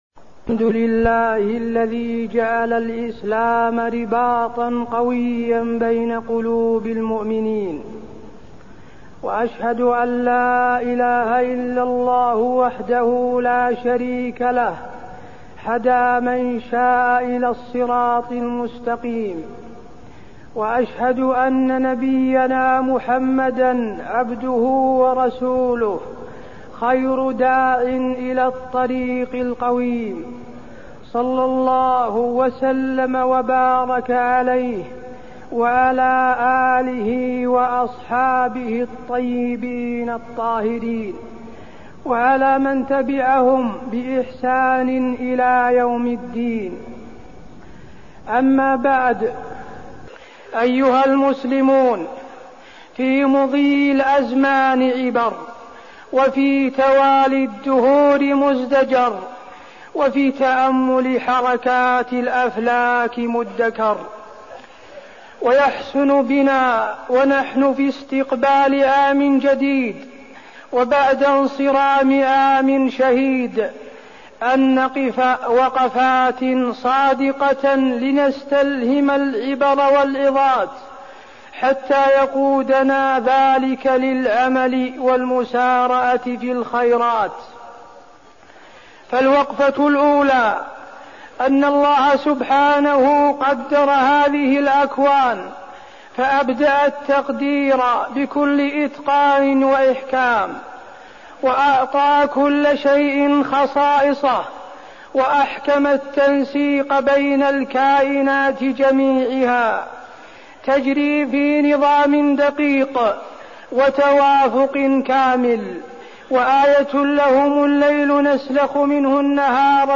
تاريخ النشر ٥ محرم ١٤١٩ هـ المكان: المسجد النبوي الشيخ: فضيلة الشيخ د. حسين بن عبدالعزيز آل الشيخ فضيلة الشيخ د. حسين بن عبدالعزيز آل الشيخ وقفات مع العام الجديد